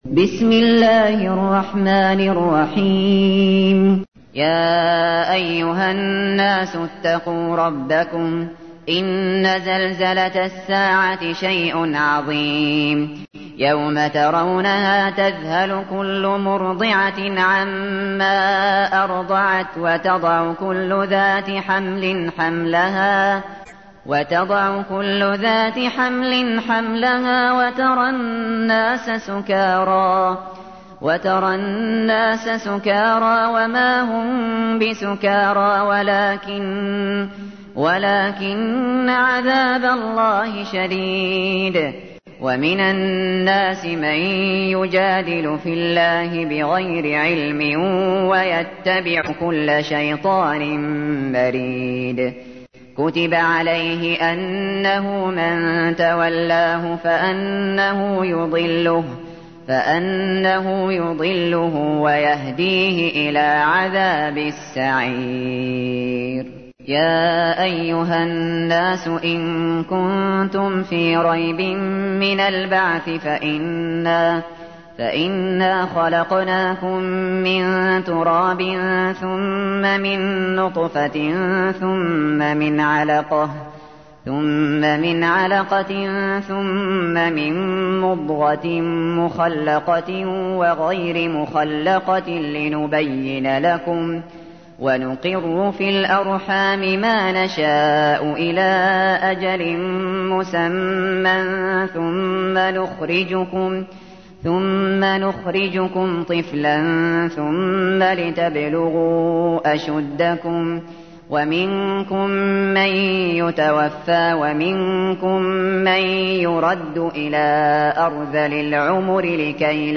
تحميل : 22. سورة الحج / القارئ الشاطري / القرآن الكريم / موقع يا حسين